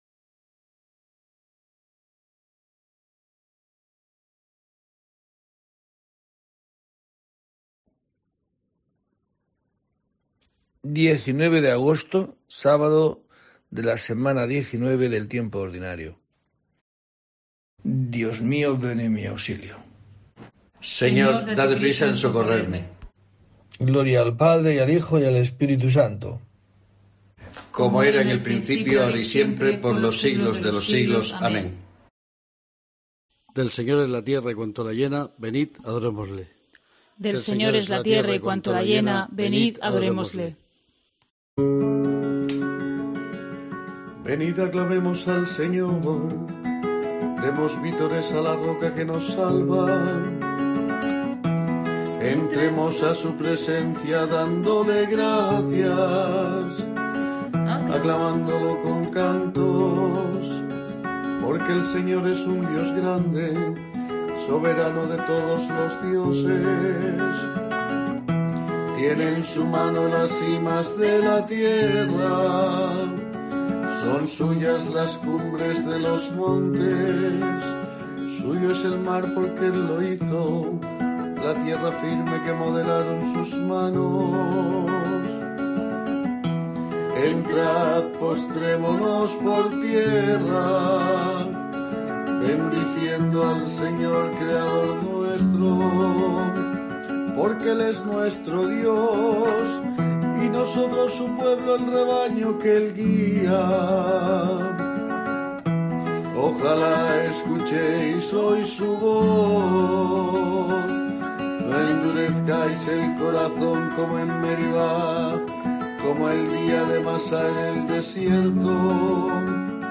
En este día tiene lugar una nueva oración en el rezo de Laudes.